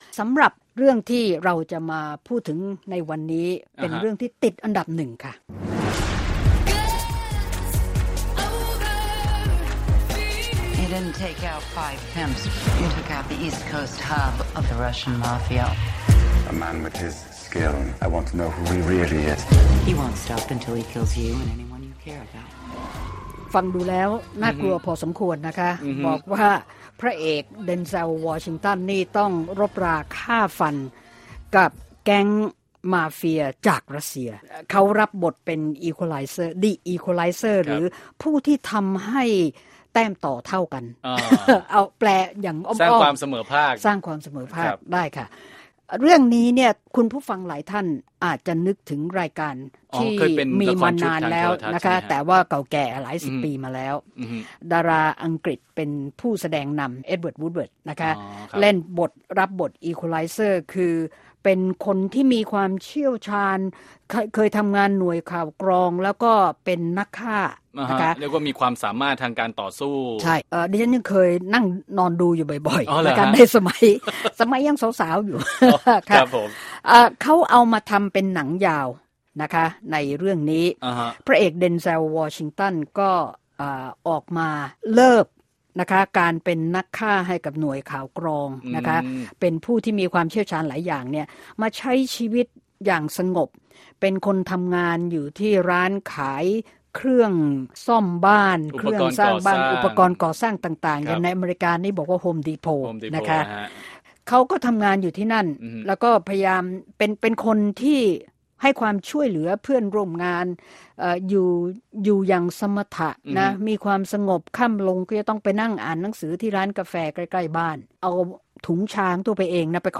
Movie Review: Egualizer